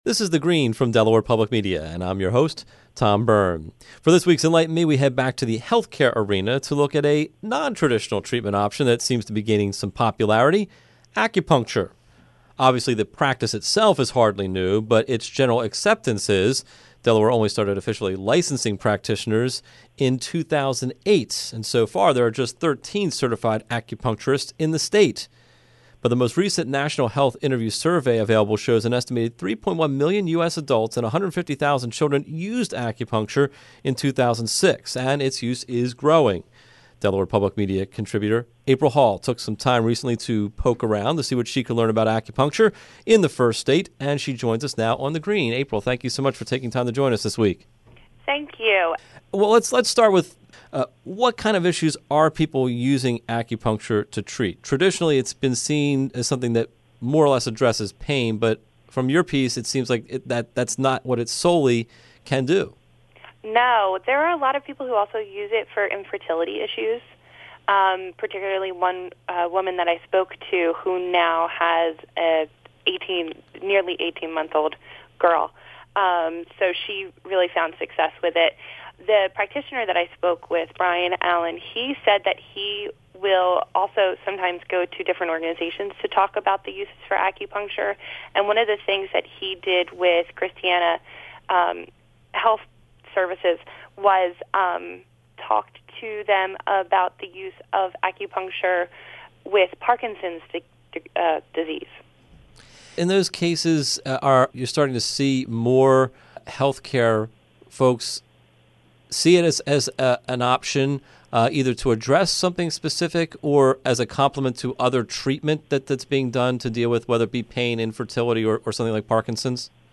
My Recent Radio Interview
Here is a recording of the Radio Broadcast.